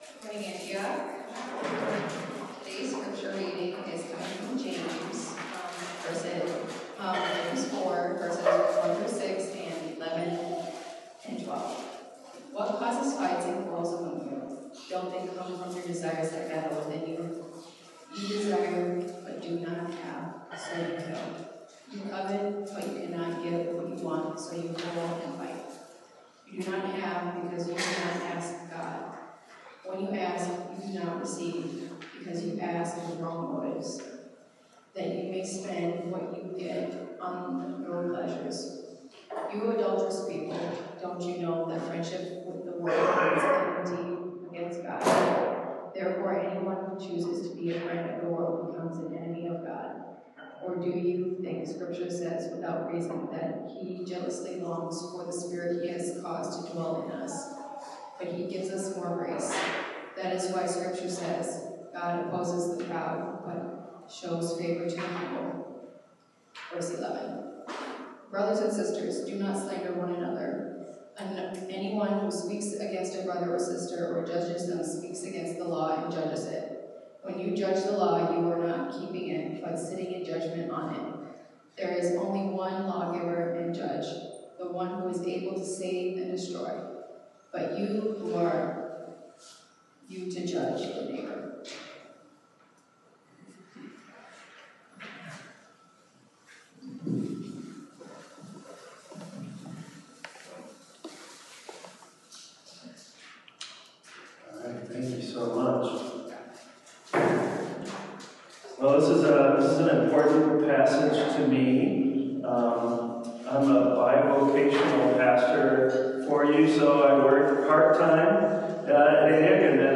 Sermon: James: Idols of the Heart